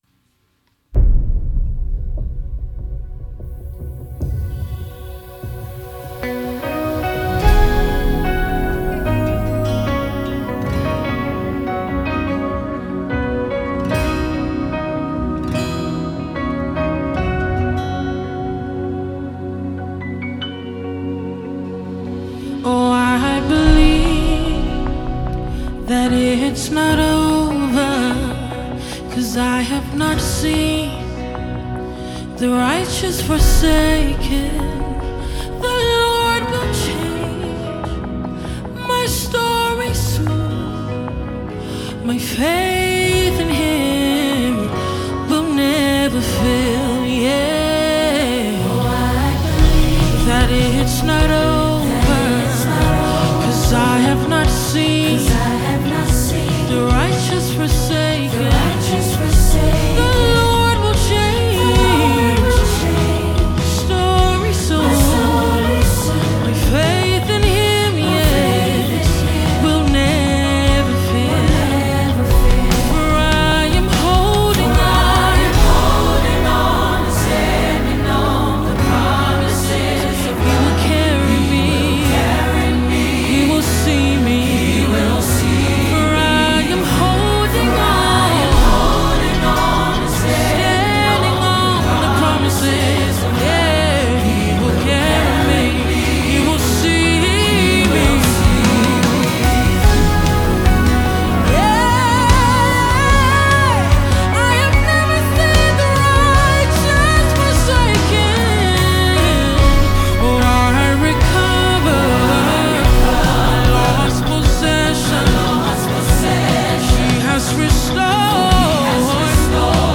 new power song
Gospel music group